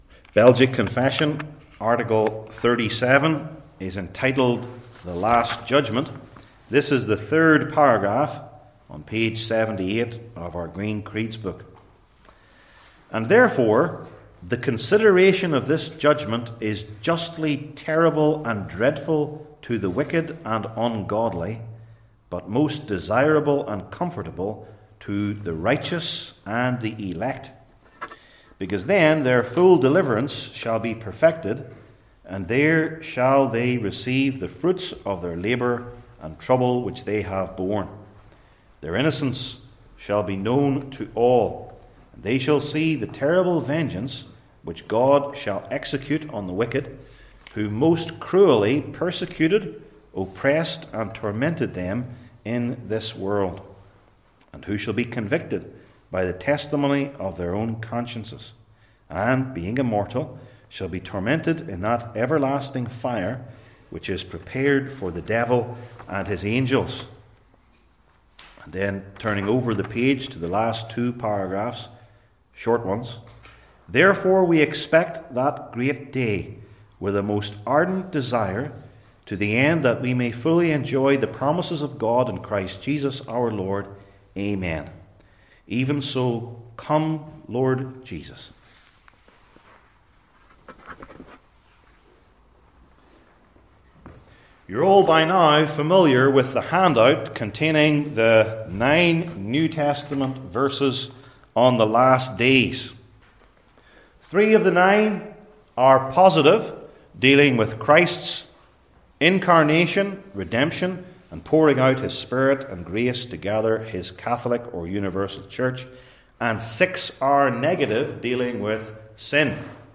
Service Type: Belgic Confession Classes